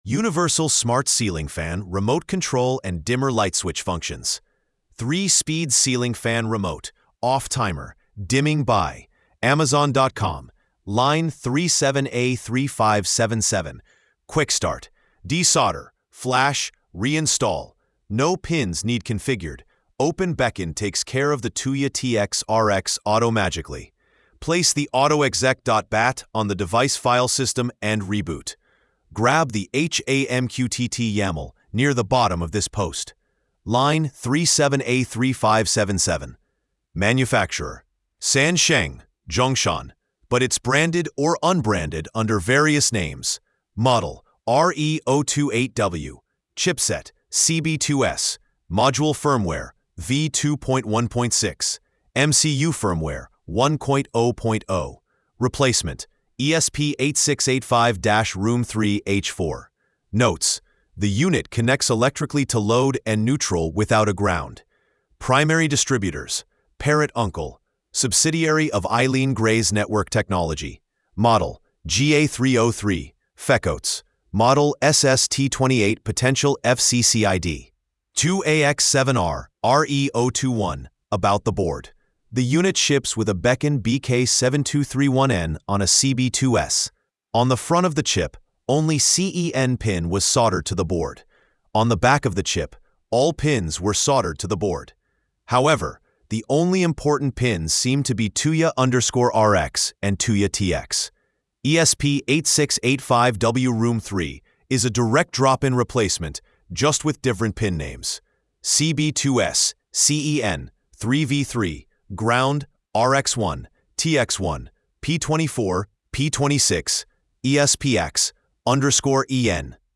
📢 Listen (AI):